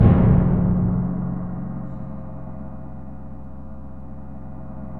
Index of /90_sSampleCDs/Roland LCDP03 Orchestral Perc/PRC_Orch Bs Drum/PRC_Grongkas